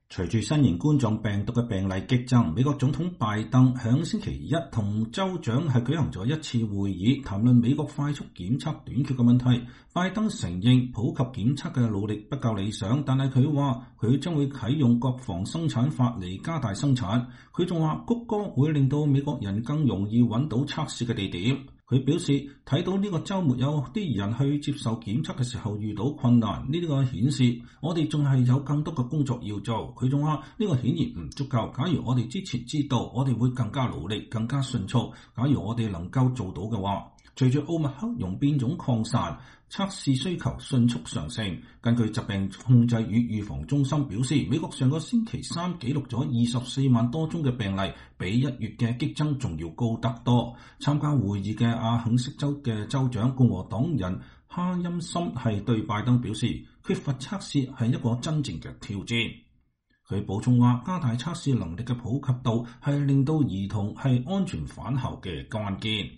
拜登總統在白宮大院內的艾森豪威爾行政大樓南院禮堂參加白宮新冠疫情響應團隊與全國州長協會的定期電話會議。